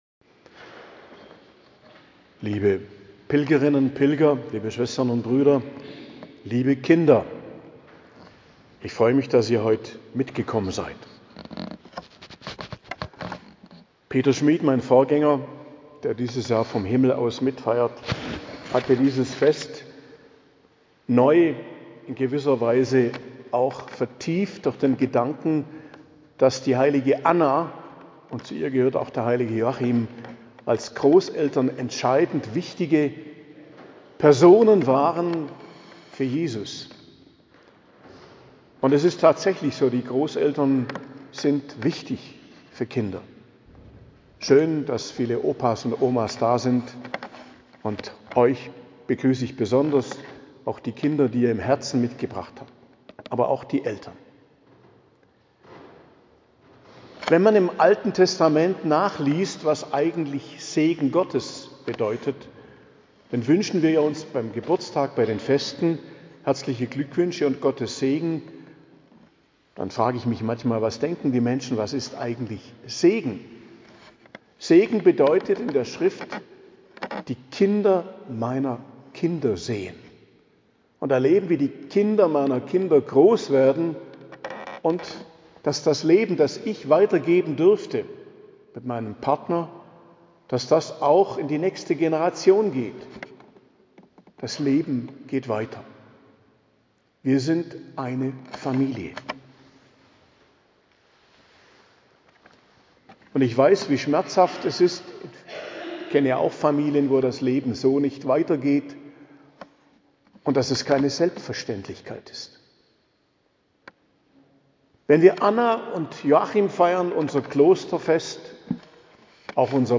Predigt zum 17. Sonntag im Jahreskreis, 28.07.2024 ~ Geistliches Zentrum Kloster Heiligkreuztal Podcast